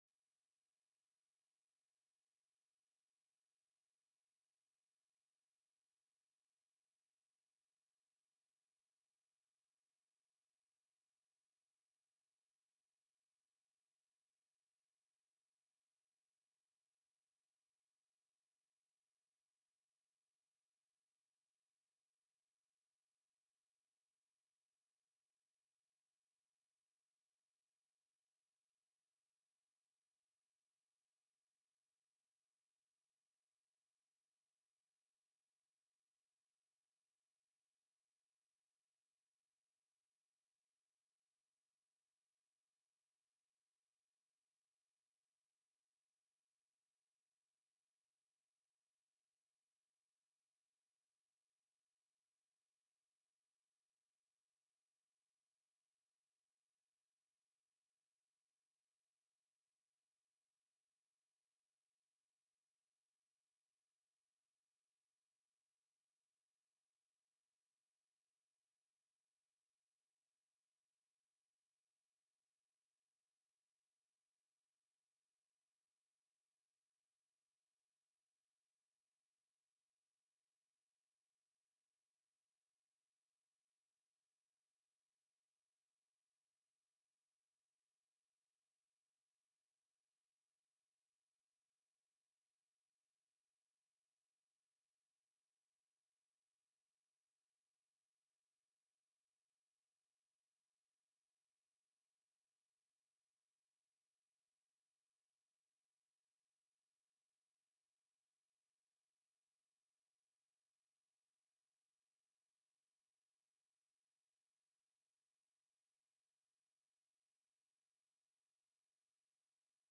05/15/2025 09:00 AM House FINANCE